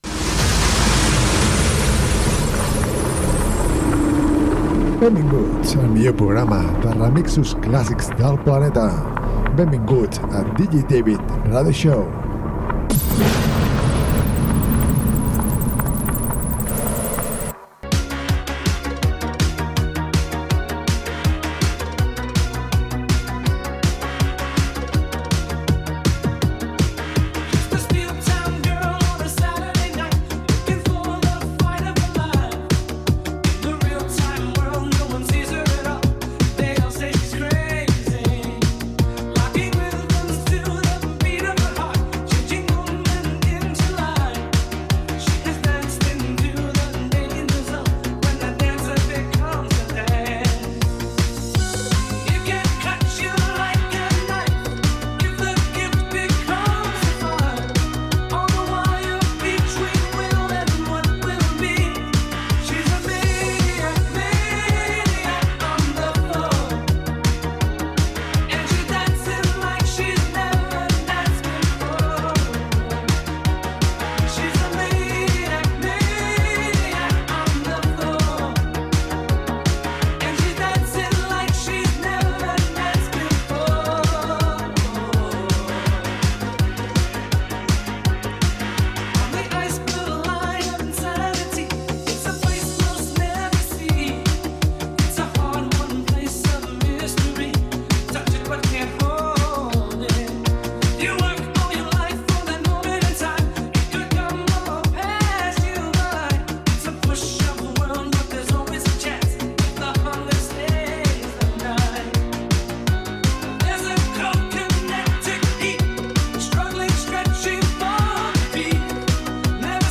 remixos classics